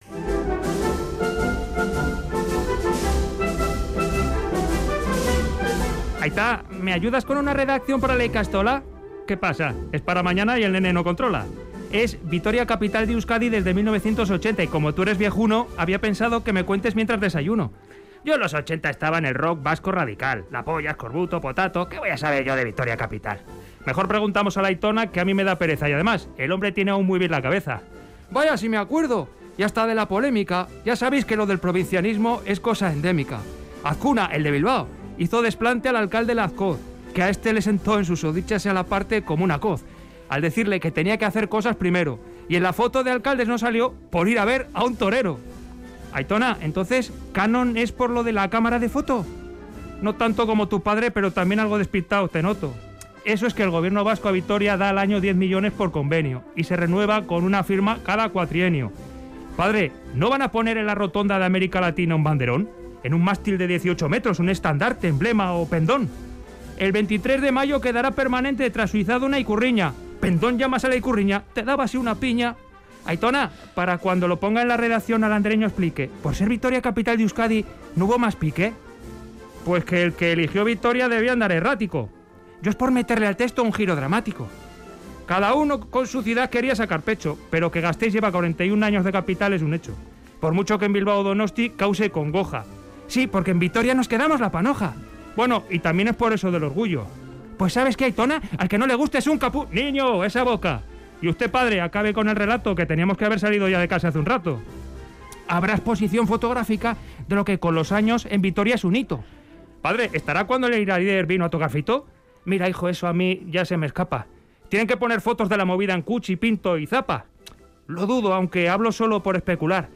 Crónica en verso: Vitoria, capital de Euskadi
Audio: Unas rimas con humor para contar la historia de Gasteiz como capital; aniversario, exposición, canon y tal...